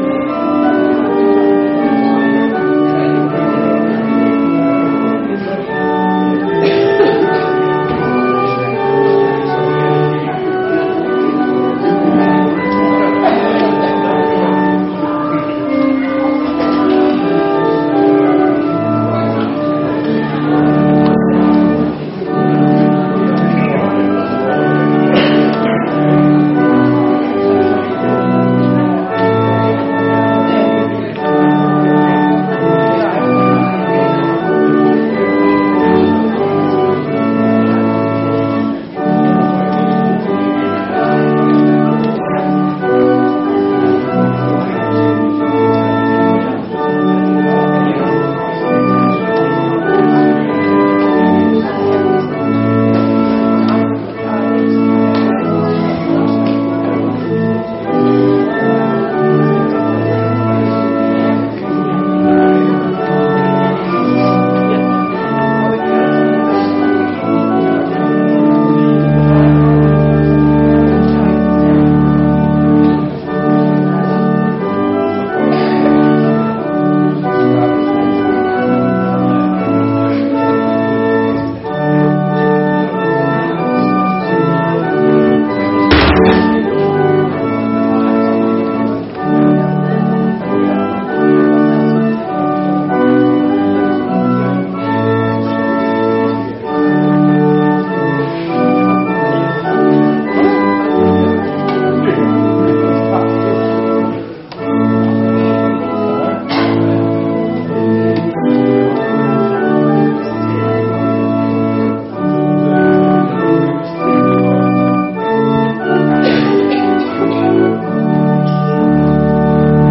Gottesdienst Sonntag 04.01.2026 | Evangelisch-altreformierte Kirchengemeinde Laar